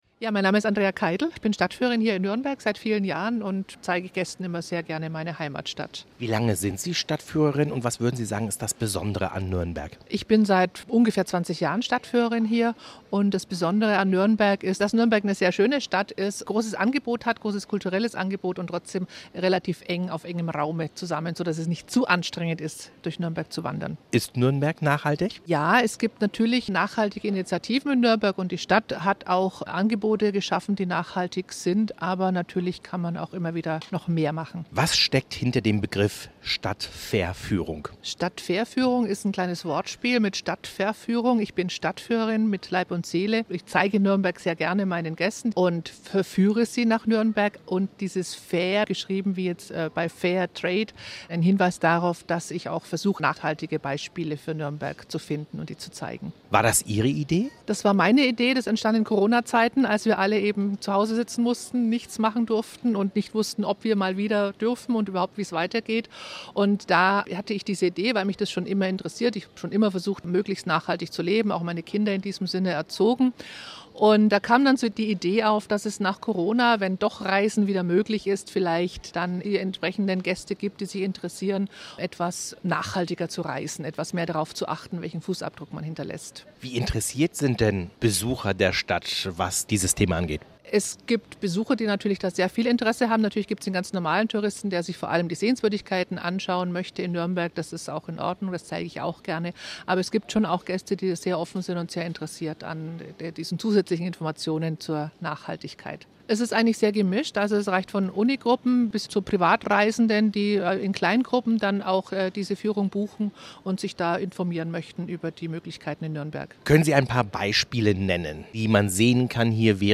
Im Interview verrät sie worum es geht und was es zu entdecken gibt - von der Stadtgeschichte bis zum fair gehandelten Kaffee.